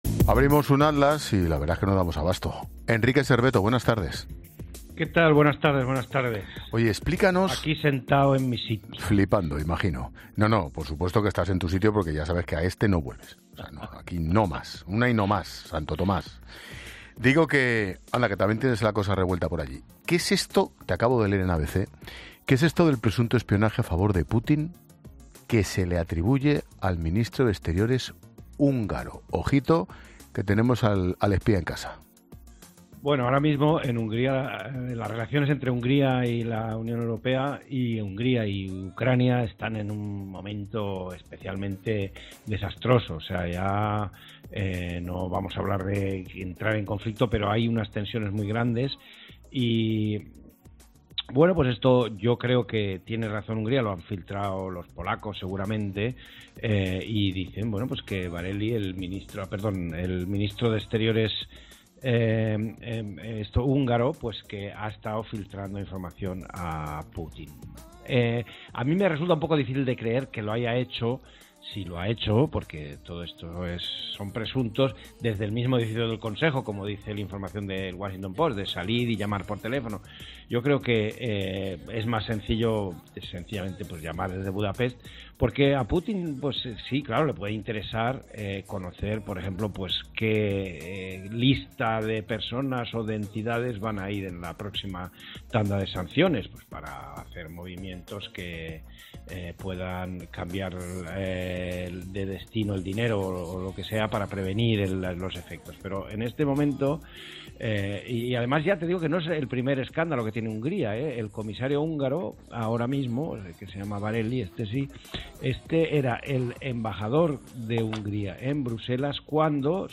Con Ángel Expósito